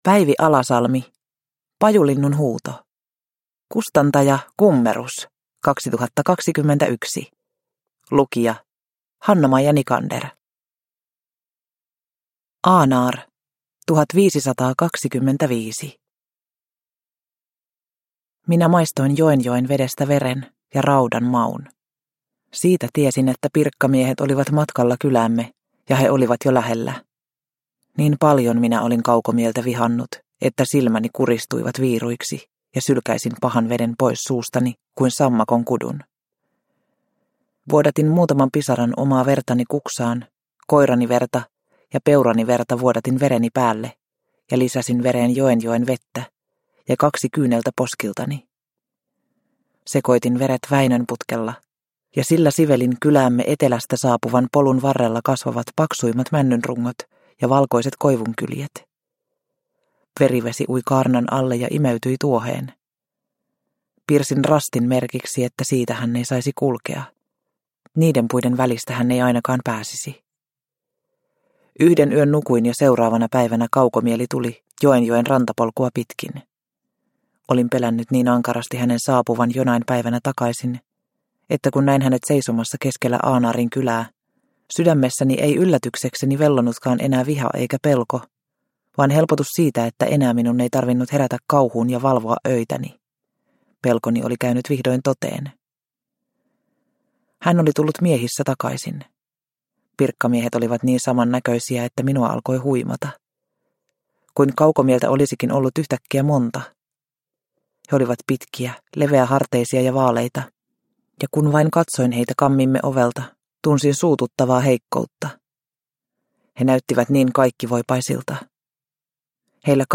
Pajulinnun huuto – Ljudbok – Laddas ner